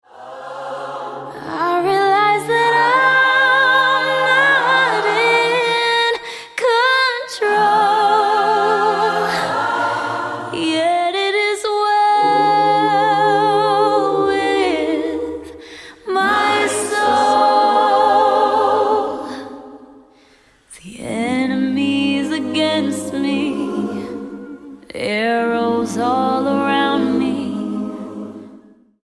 STYLE: Gospel
gorgeous a cappella track